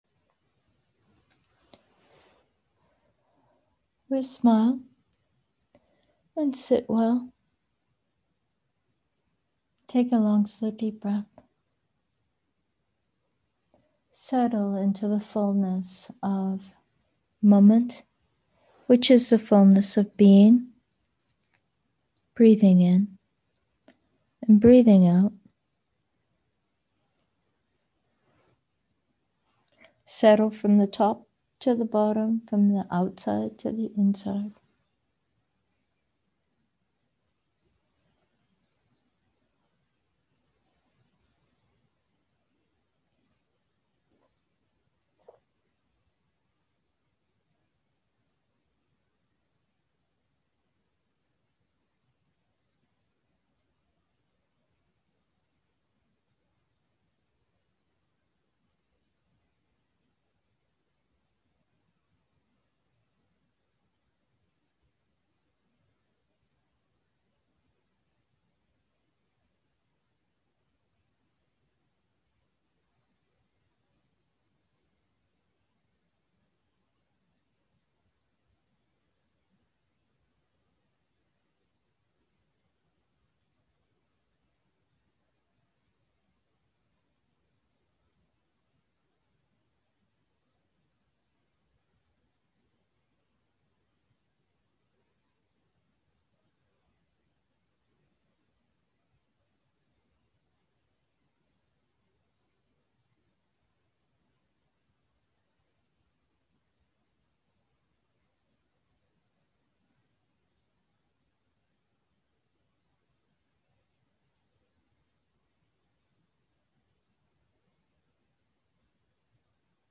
Meditation: scent and the natural state